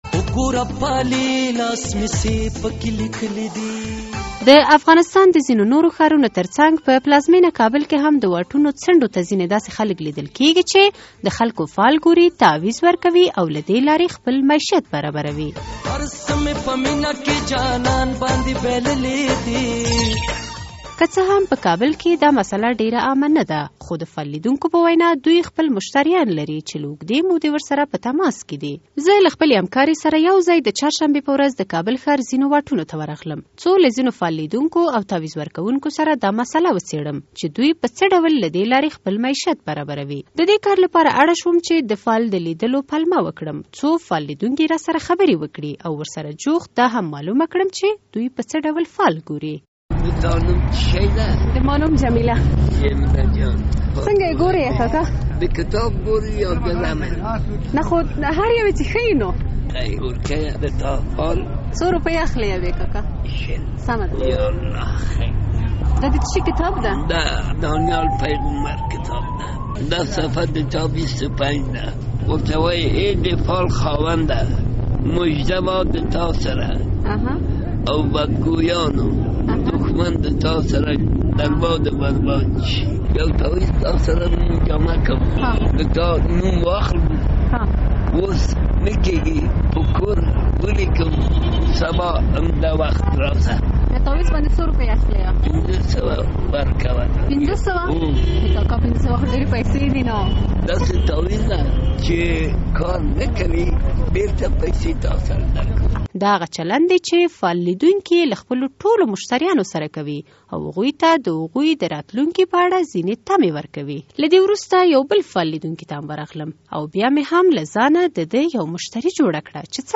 د فال لیدلو او تعویذ لیکلو په اړه څیړنیز راپور